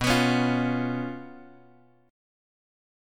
B Suspended 2nd Flat 5th